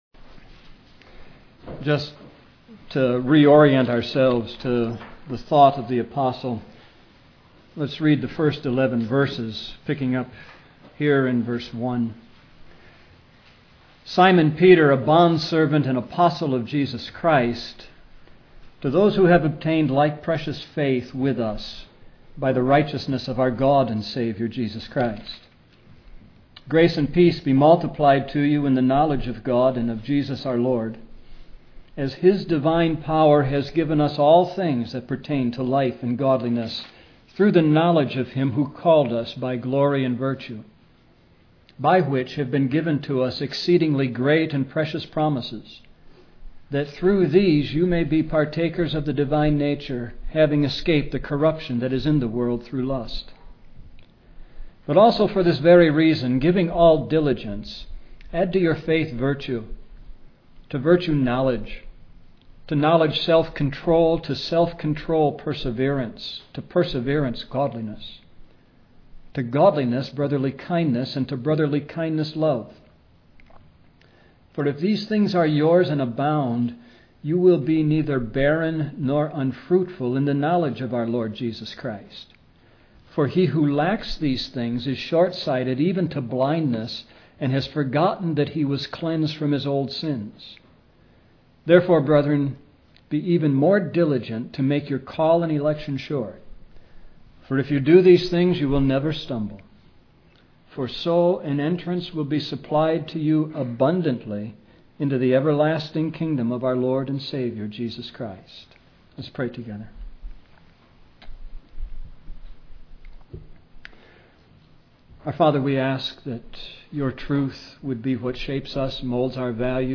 A message from the series "Sermons & Recordings."